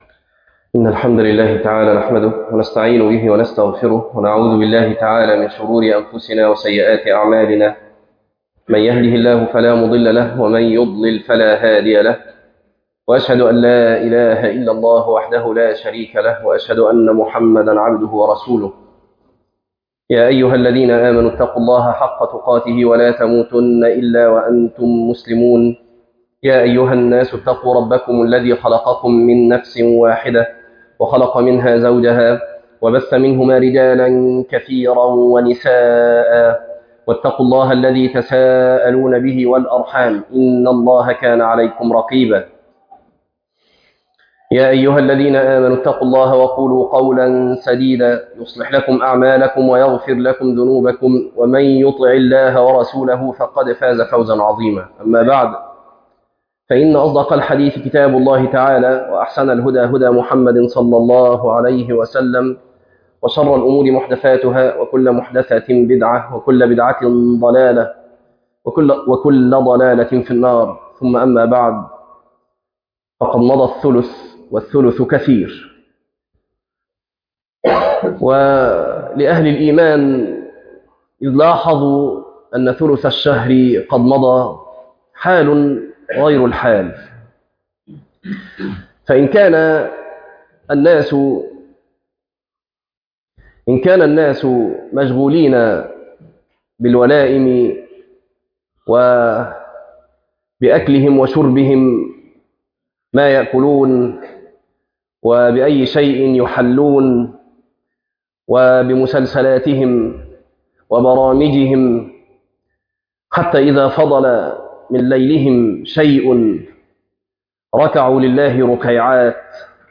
تفاصيل المادة عنوان المادة شهر الغفران - خطبة تاريخ التحميل الأثنين 13 ابريل 2026 مـ حجم المادة 12.38 ميجا بايت عدد الزيارات 11 زيارة عدد مرات الحفظ 6 مرة إستماع المادة حفظ المادة اضف تعليقك أرسل لصديق